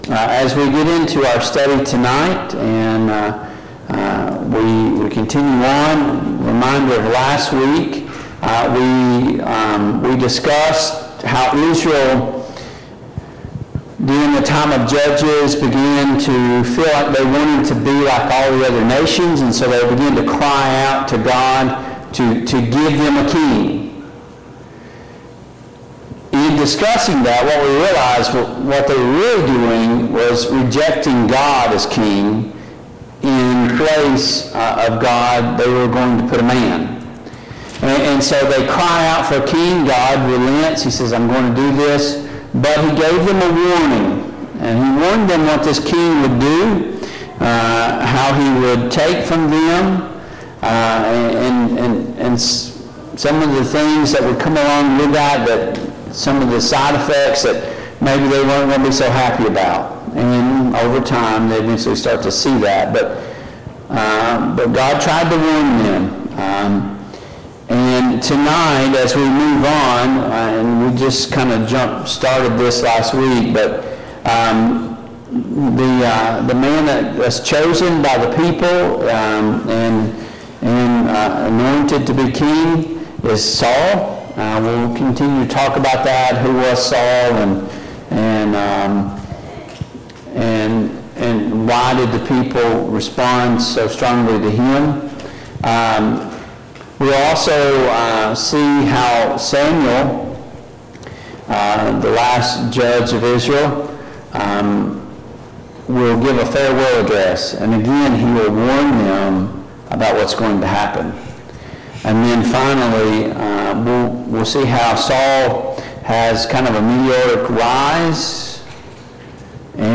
Mid-Week Bible Study Download Files Notes Topics